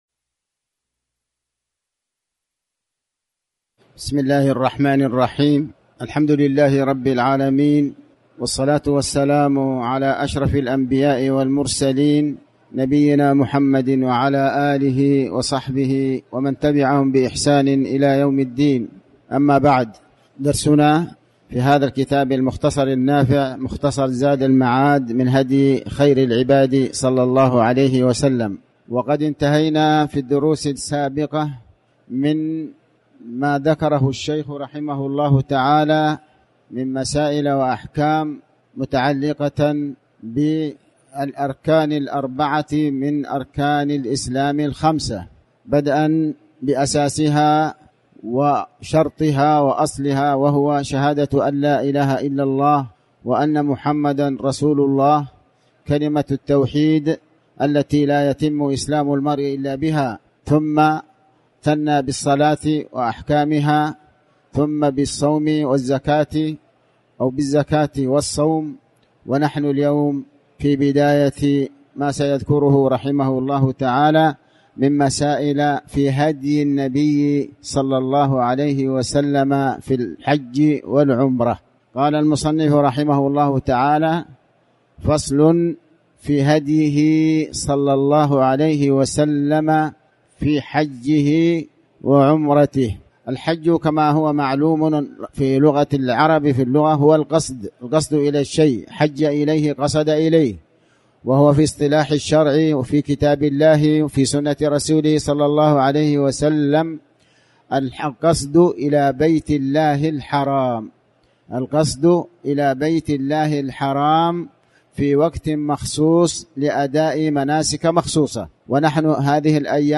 تاريخ النشر ٢٣ شوال ١٤٤٠ هـ المكان: المسجد الحرام الشيخ: علي بن عباس الحكمي علي بن عباس الحكمي هديه صلى الله عليه وسلم في حجه وعمرته